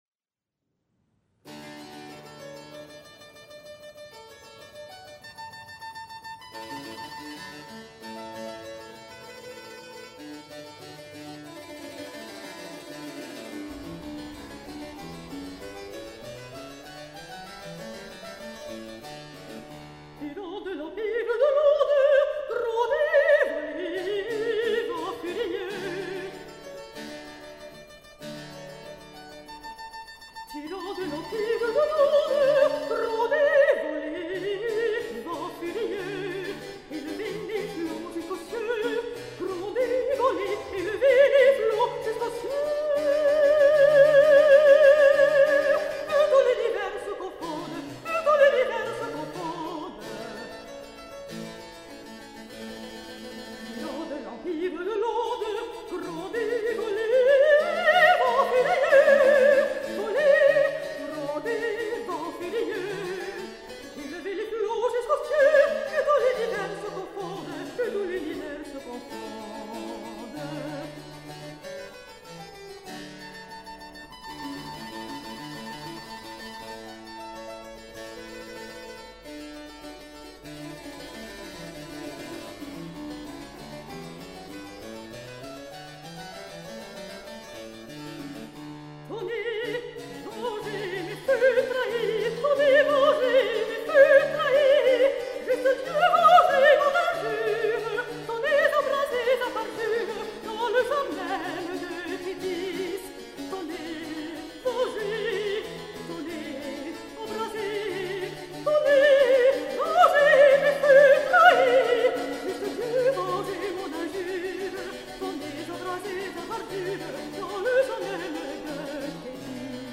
La mort de Didon, Montéclair (fin). Clavecin
Artiste Lyrique